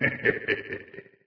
snd_chuckle.ogg